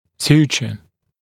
[‘s(j)uːʧə][‘с(й)у:чэ]хирургический шов; наложение шва, сшивание; накладывать шов, сшивать